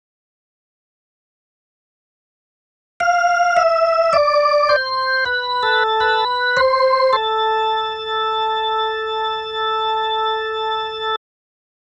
Organ 04.wav